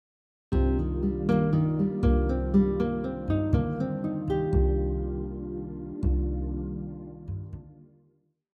This first example uses an ascending four note pattern using only the C minor 7 chord tones: C, Eb, G, and Bb.
minor 7 arpeggio example 1